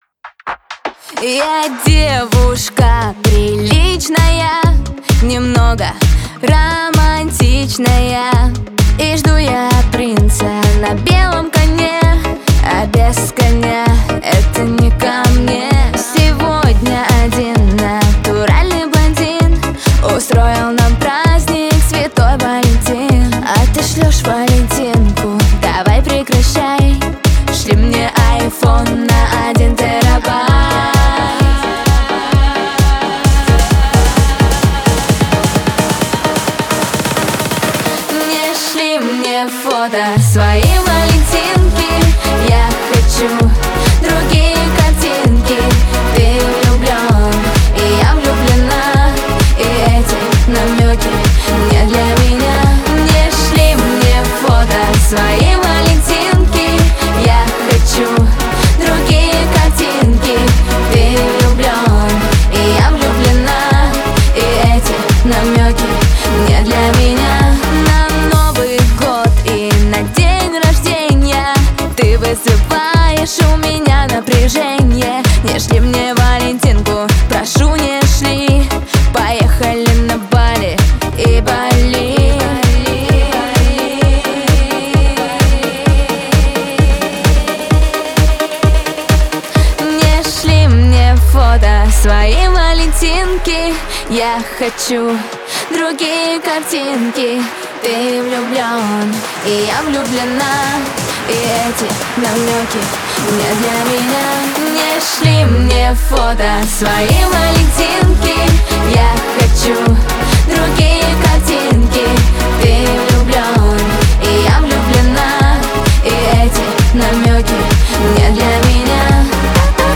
Категория Русская музыка